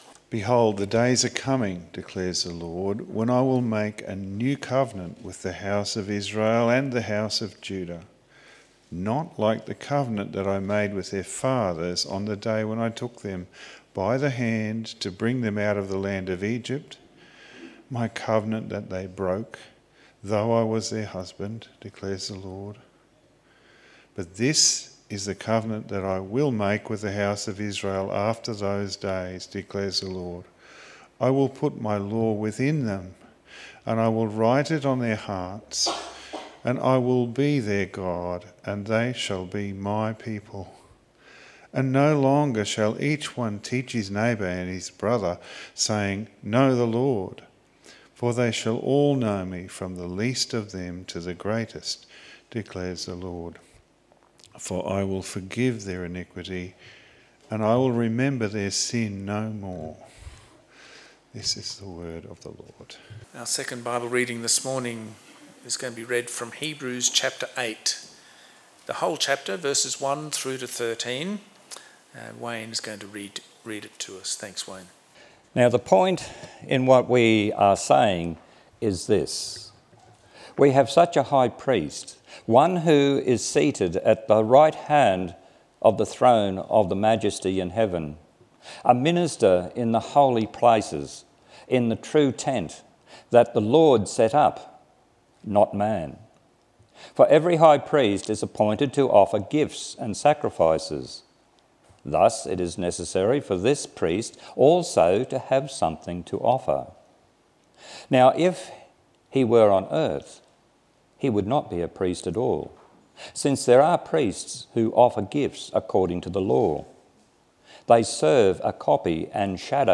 22/02/2026 Jesus- The Mediator of a Better Covenant Preacher